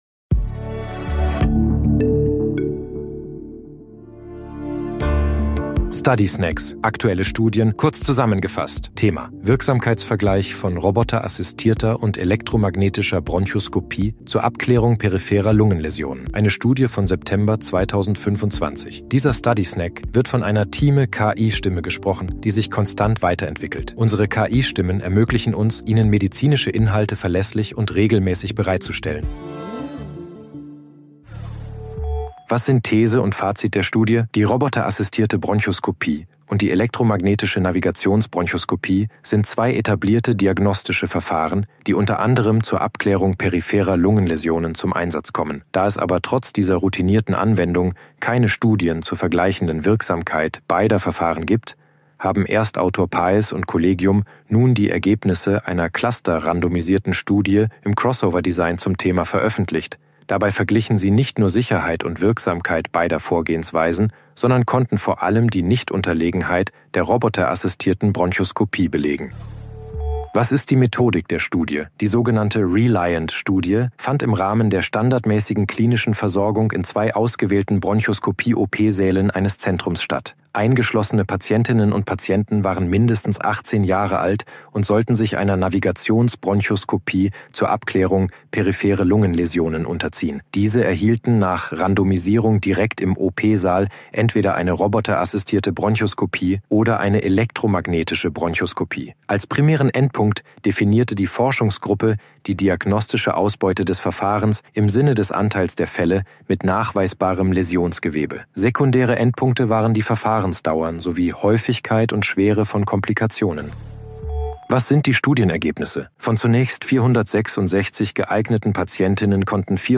künstlicher Intelligenz (KI) oder maschineller